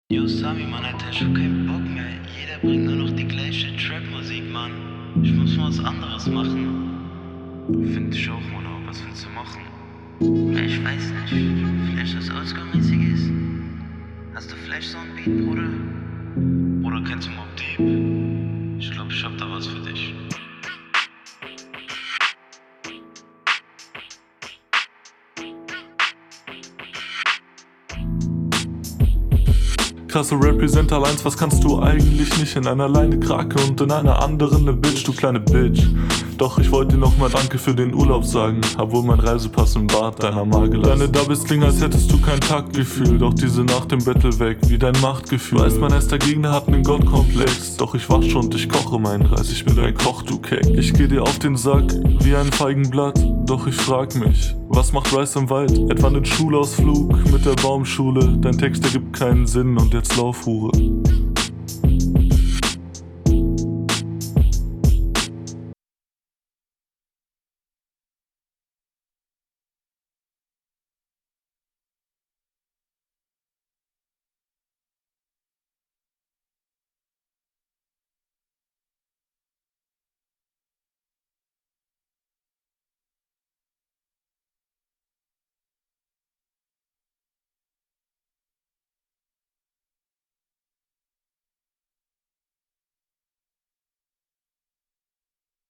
du konterst gut, aber deine stimme und flow kann hier nicht mithalten weshalb ich den …
Soundqualität: Klingt ungemischt aber man versteht alles Flow: Mehr variationen als der andere aber einen …